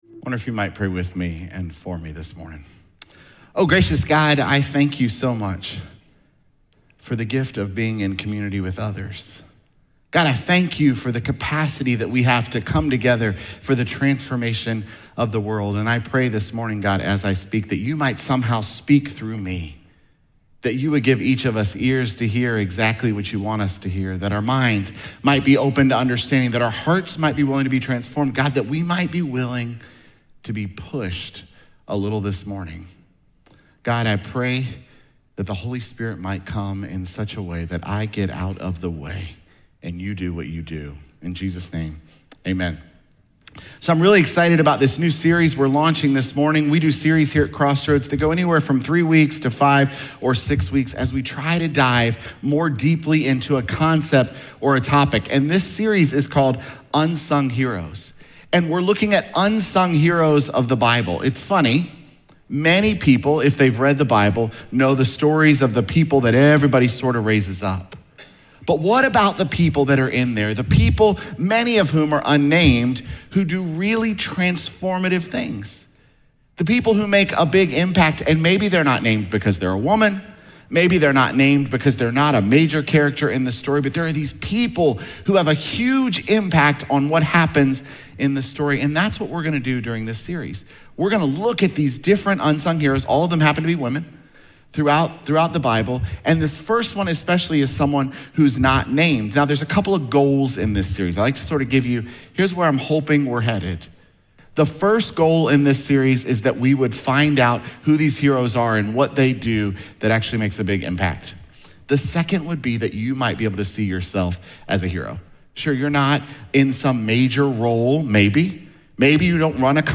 Jul20SermonPodcast.mp3